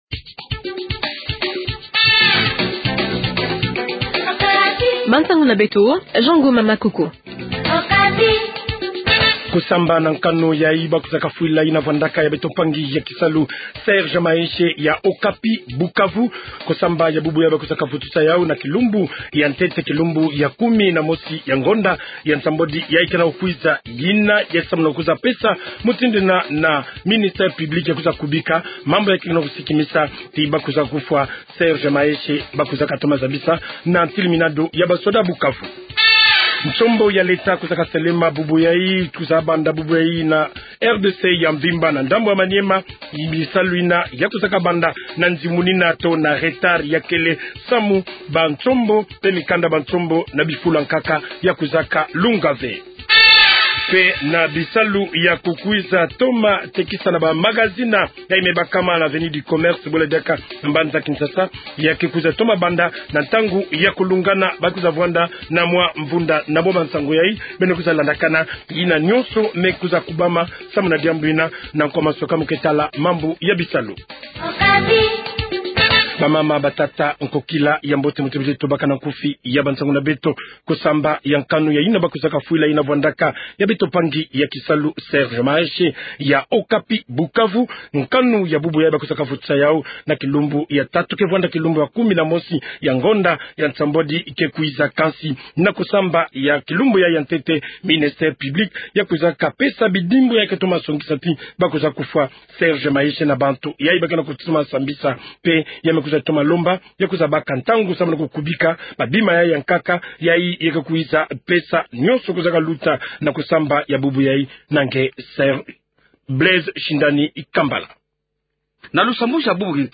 Journal Kikongo Soir